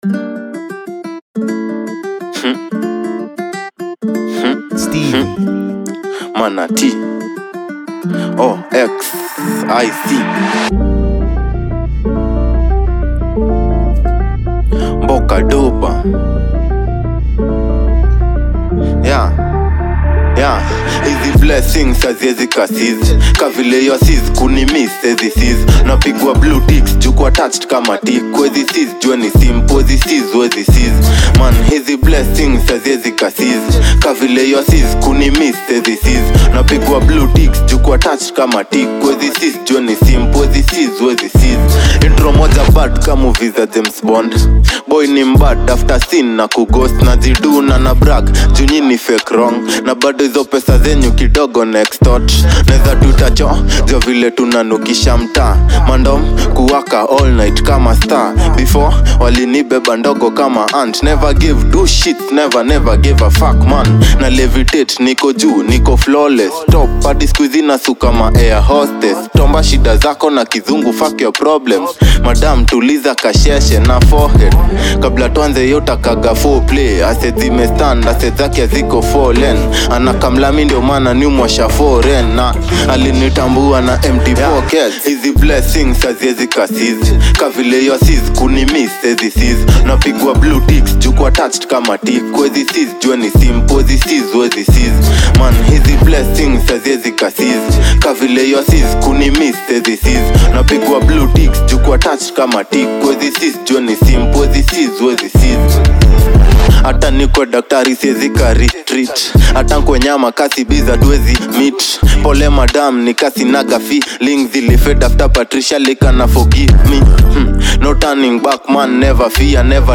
With its raw vibe and fearless energy